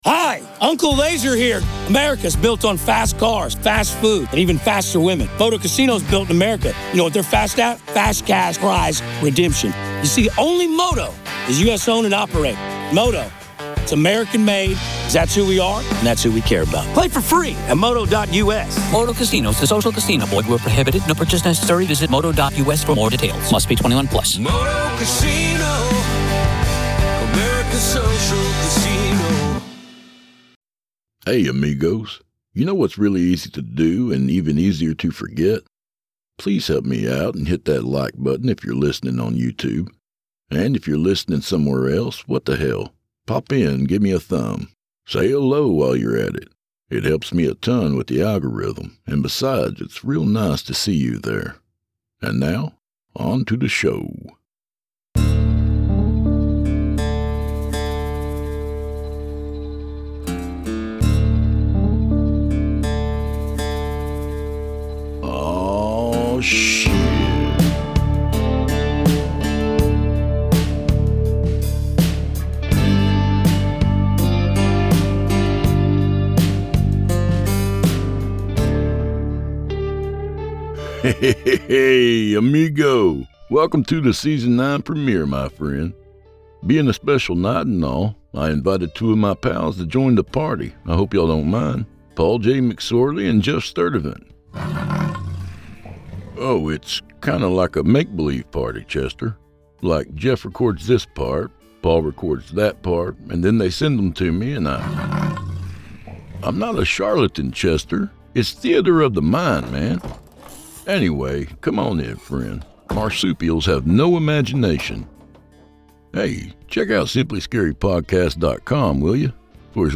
With a full-cast performance, razor-sharp wit, and a house that may have plans of its own, this story blends horror, humor, and just the right amount of chaos to leave you howling in all the wrong ways.